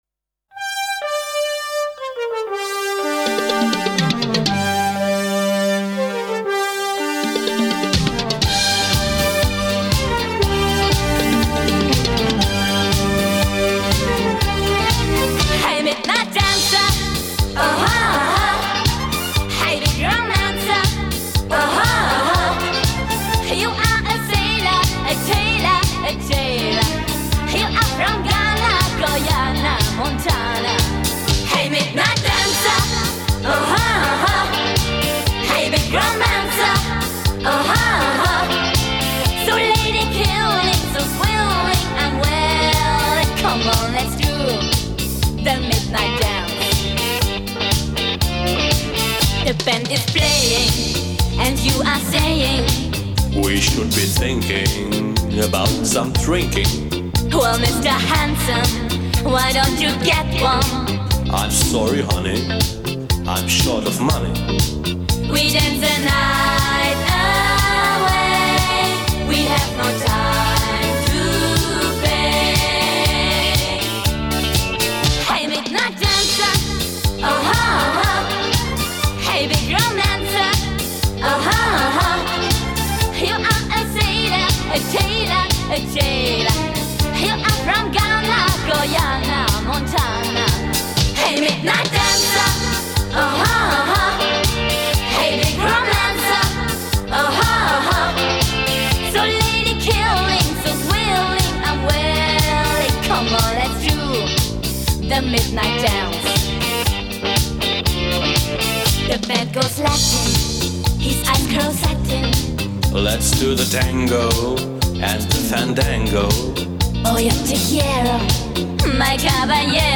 Галерея Music Disco 80